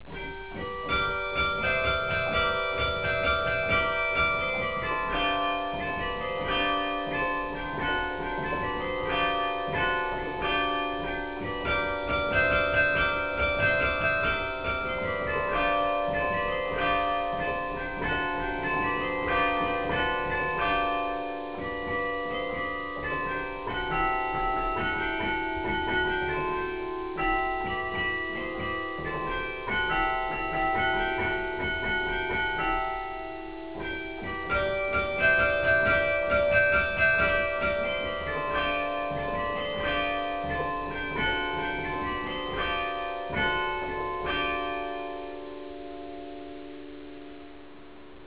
Le carillon du beffroi de Bergues - Les Ritournelles - L'Heure
C'est la mélodie la plus longue des 4 ritournelles.
X Ecouter l'ancienne ritournelle automatique de l'heure...